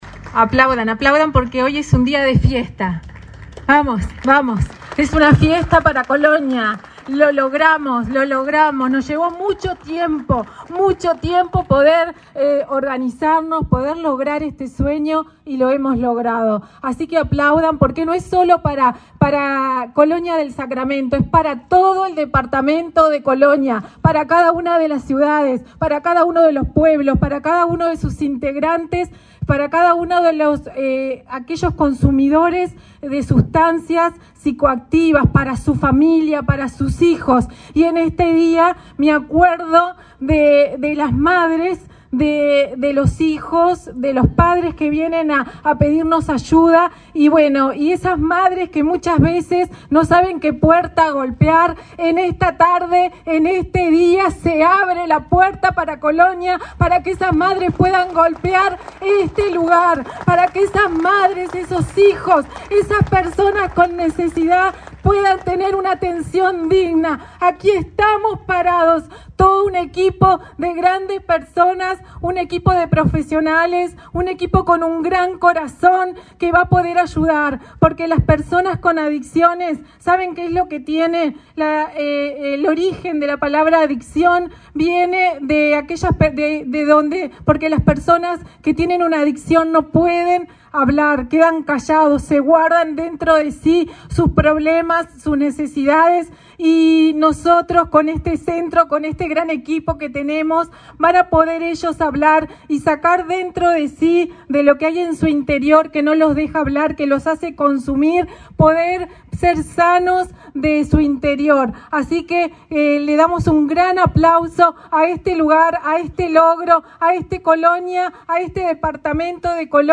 Acto de inauguración de centro Esperanza de Colonia
Acto de inauguración de centro Esperanza de Colonia 19/07/2023 Compartir Facebook X Copiar enlace WhatsApp LinkedIn La Junta Nacional de Drogas, la Dirección Departamental de Salud y la Intendencia de Colonia inauguraron, este 19 de julio, un centro de prevención de adicciones, tratamiento y rehabilitación. Participaron en el evento la directora departamental de Salud, María Alejandra Torres, y el titular de la Secretaría Nacional de Drogas, Daniel Radío.